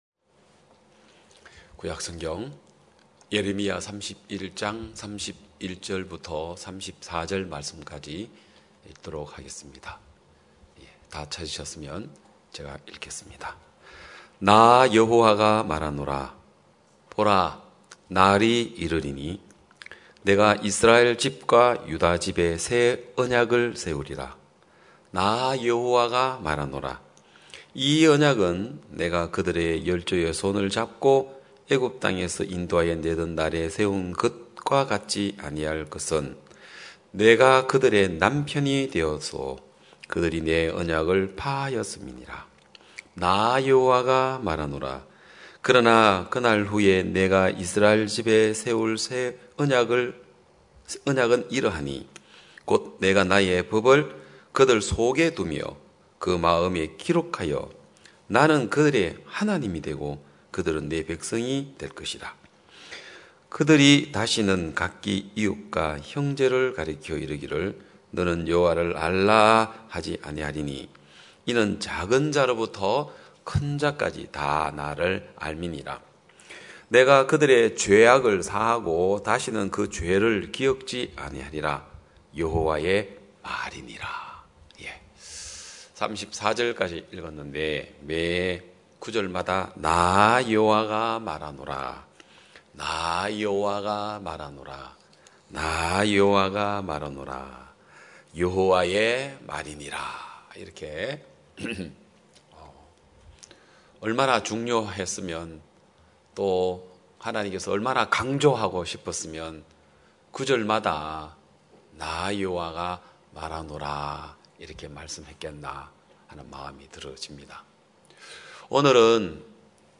2021년 8월 15일 기쁜소식양천교회 주일오전예배
성도들이 모두 교회에 모여 말씀을 듣는 주일 예배의 설교는, 한 주간 우리 마음을 채웠던 생각을 내려두고 하나님의 말씀으로 가득 채우는 시간입니다.